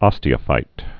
(ŏstē-ə-fīt)